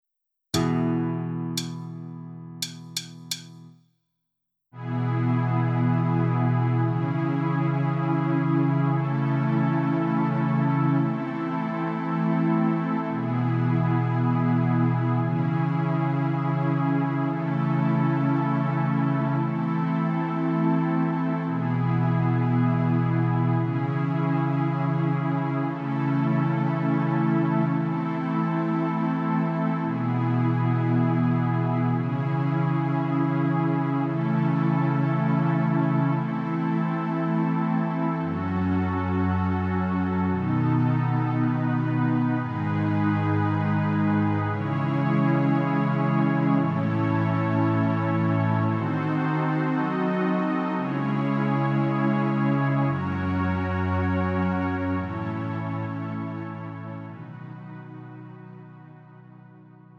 음정 -1키 4:20
장르 가요 구분